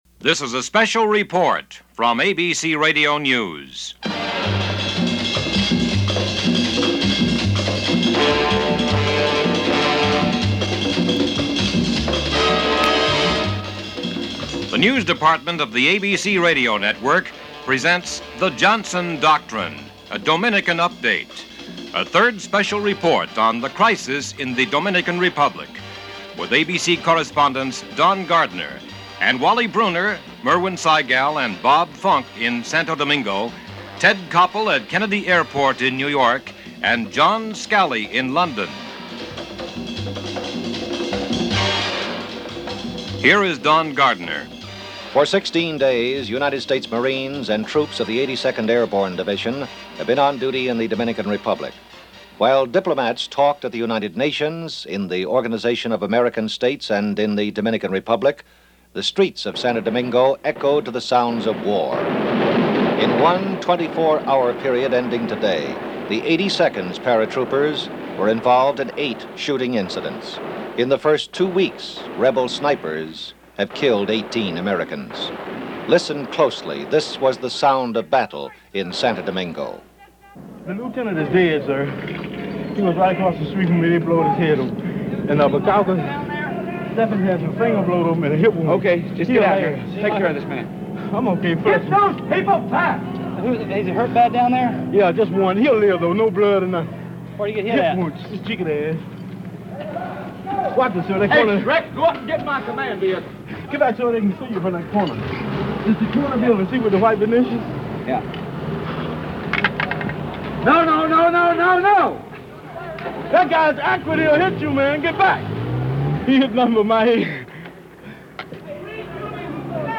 May 12, 1965 - Santo Domingo: "Just Like Dodge City" - The Johnson Doctrine - ABC Radio special report on crisis in Santo Domingo.